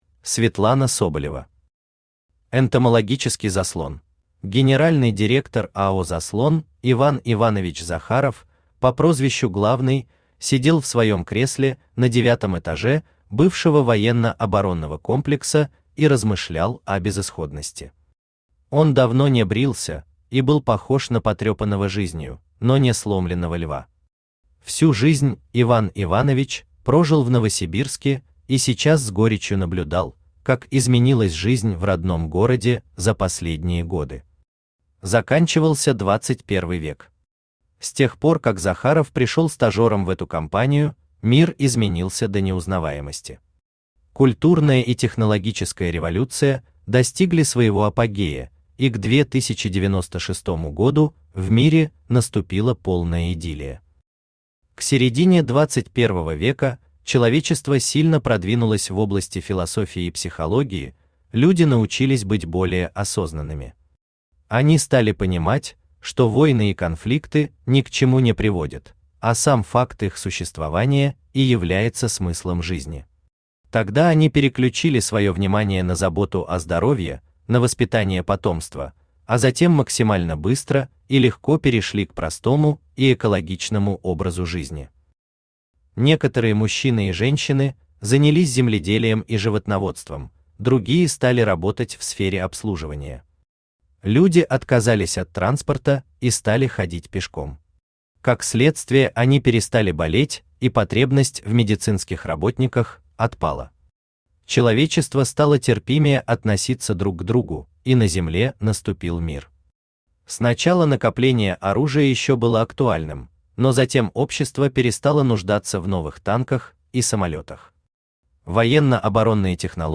Аудиокнига Энтомологический Заслон | Библиотека аудиокниг
Aудиокнига Энтомологический Заслон Автор Светлана Соболева Читает аудиокнигу Авточтец ЛитРес.